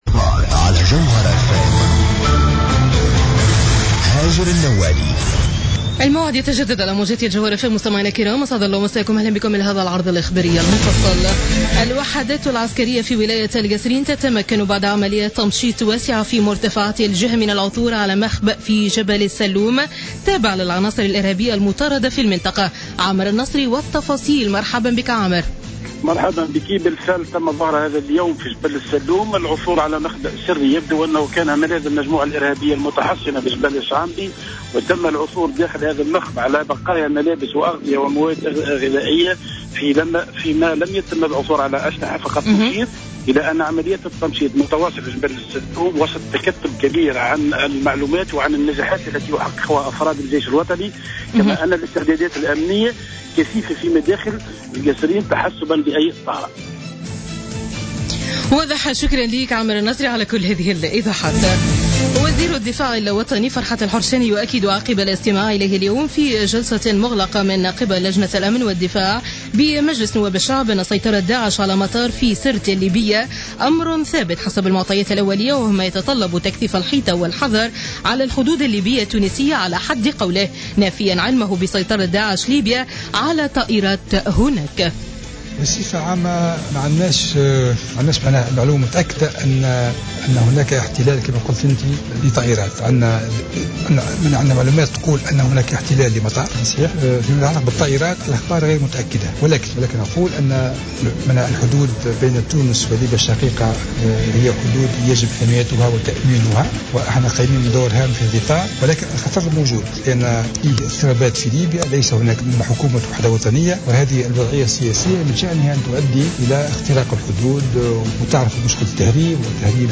نشرة أخبار السابعة مساء ليوم الخميس 04 جوان 2015